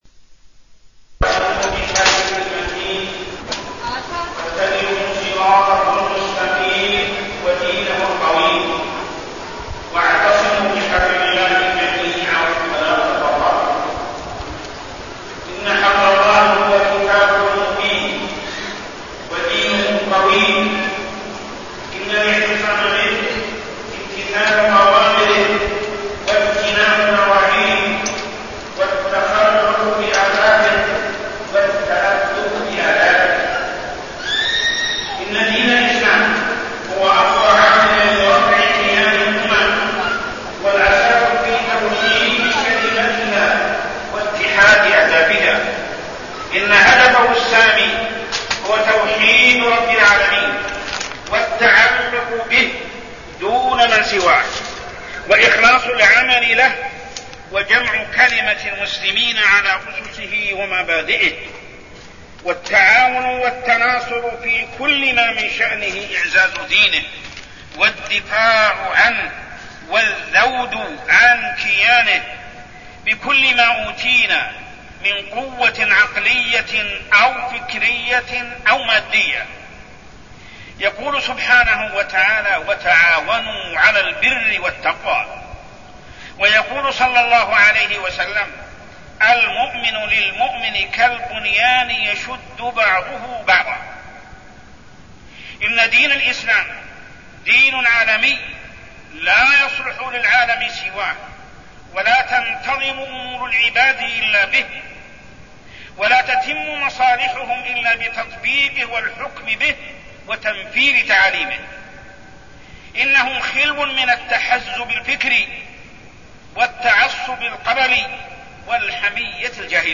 تاريخ النشر ١٣ ربيع الثاني ١٤١٦ هـ المكان: المسجد الحرام الشيخ: محمد بن عبد الله السبيل محمد بن عبد الله السبيل عالمية الإسلام